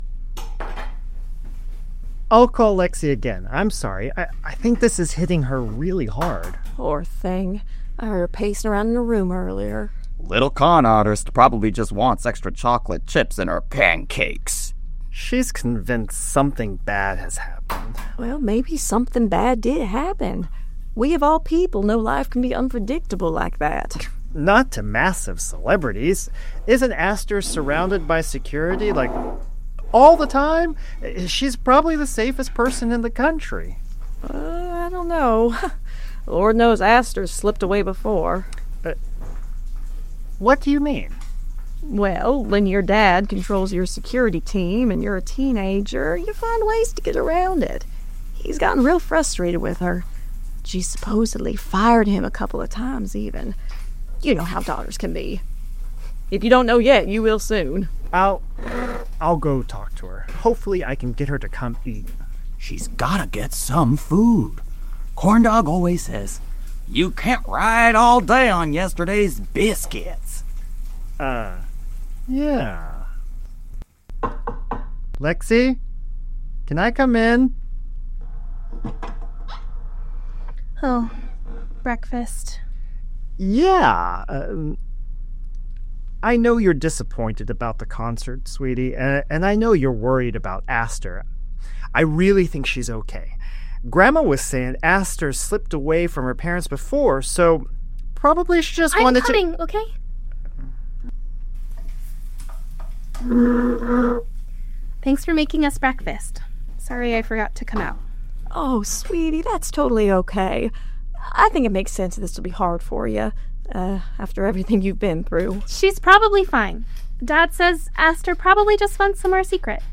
Each episode blends immersive audio drama with original songs containing clues.